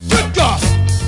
Good God Sound Effect
Download a high-quality good god sound effect.